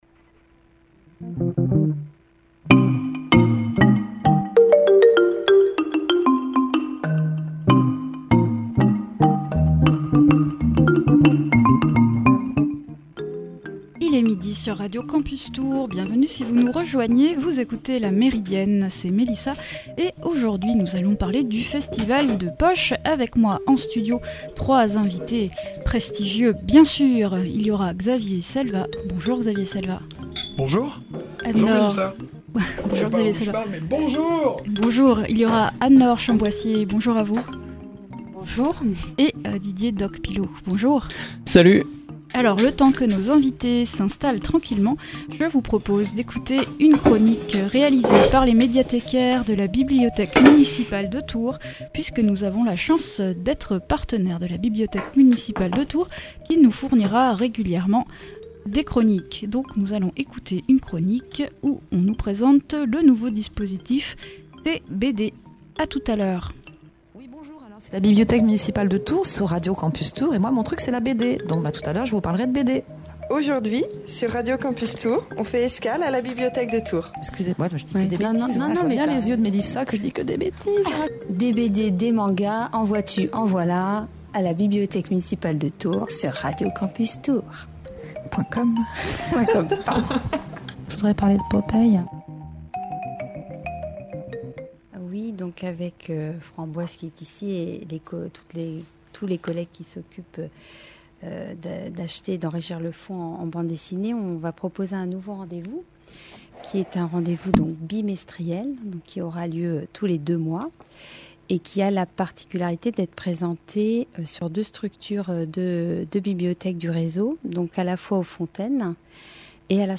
Nous ouvrons cette émission avec une chronique de nos médiathécaires préférés, enregistrée dans le cadre d’un partenariat entre Radio Campus et la Bibliothèque Municipale de Tours. Aujourd’hui, TBD, le nouveau rendez-vous BD !